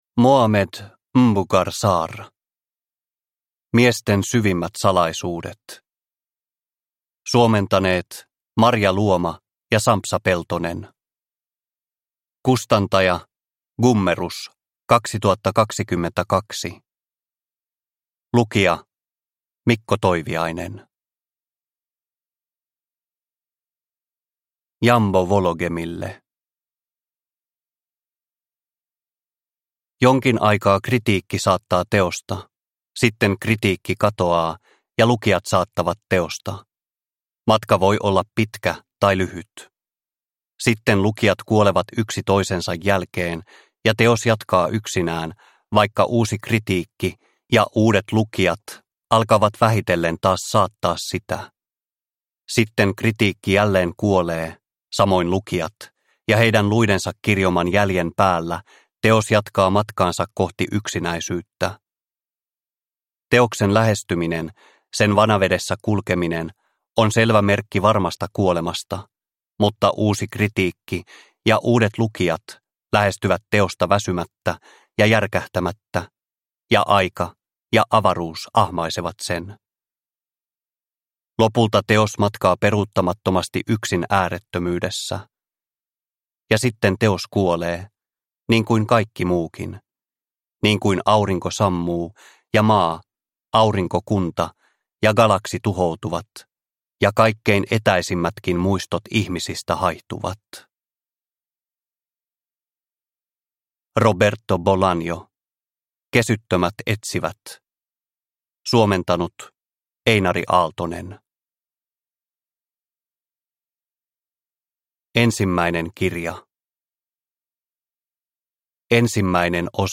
Miesten syvimmät salaisuudet – Ljudbok – Laddas ner